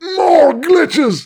goatfree04.ogg